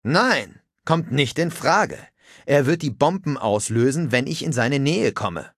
Charakter: Geisel des Predigers
Fallout 3: Audiodialoge